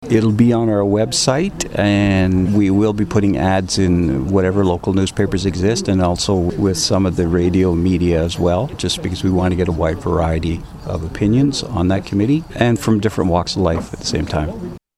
After about an hour of placard-waving surrounding the junction of Highways 60 and 41, the group retired to the serenity of the amphitheatre in Gerald Tracey Park to hear from a variety of speakers including one pragmatic Upper Tier radical.